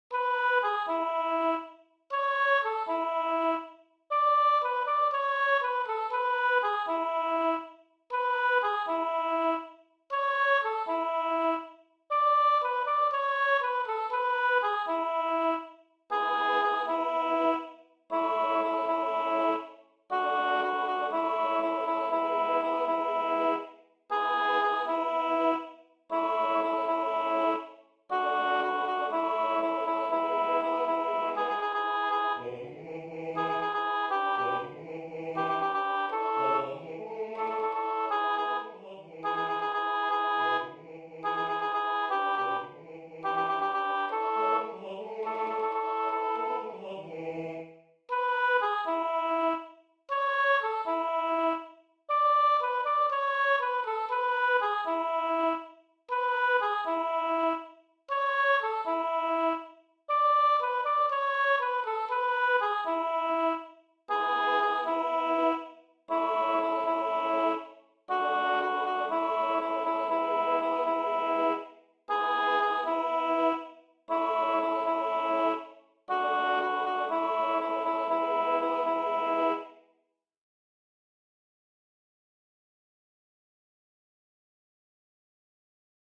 Sopran 2/Alt 1